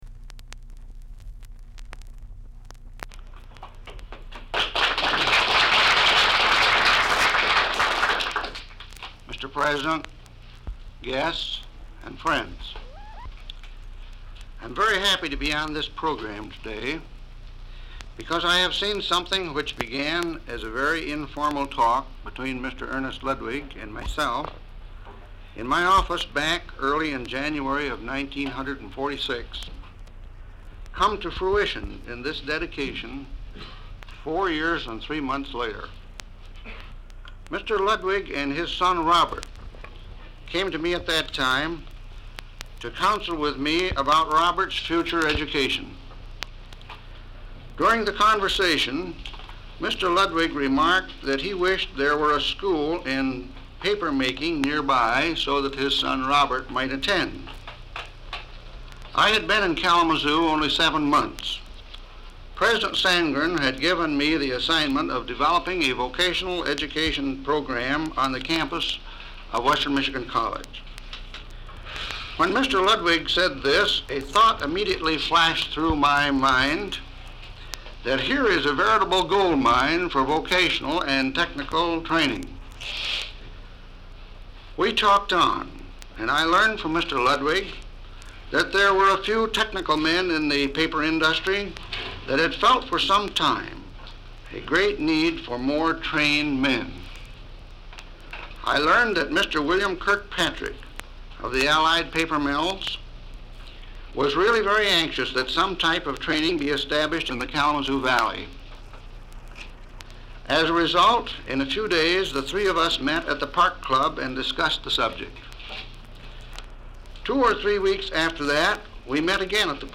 Members of the Western Michigan College faculty and administration speak at the dedication of the university's new Pulp and Paper Lab